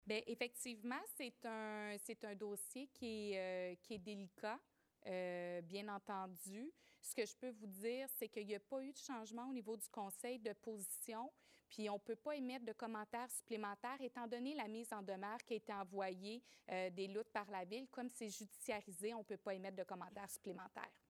De son côté, la mairesse de Granby, Julie Bourdon, a indiqué que le dossier est judiciarisé :